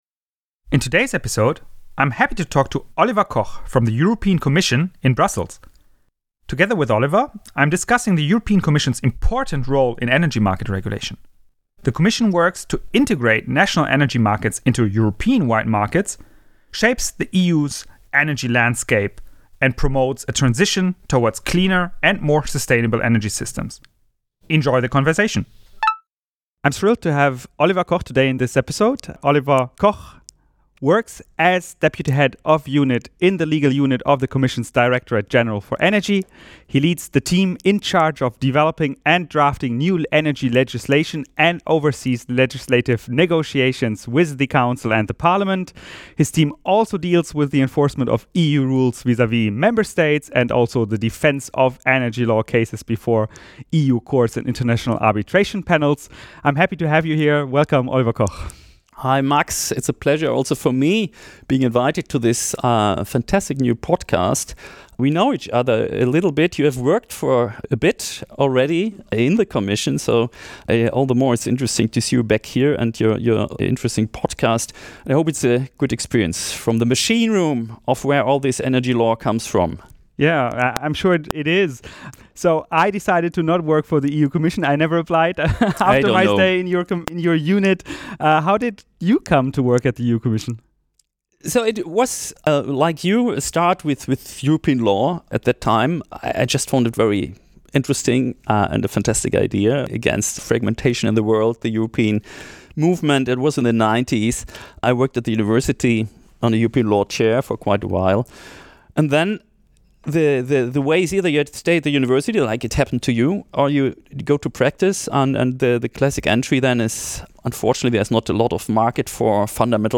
The Commission works to integrate national energy markets into European-wide markets, shapes the EU's energy landscape, and promotes a transition towards cleaner and more sustainable energy systems. Enjoy the conversation!